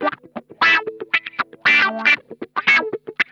Index of /90_sSampleCDs/Zero G - Funk Guitar/Partition B/VOLUME 021
THROATWAH 2.wav